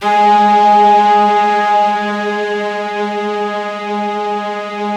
BIGORK.G#2-R.wav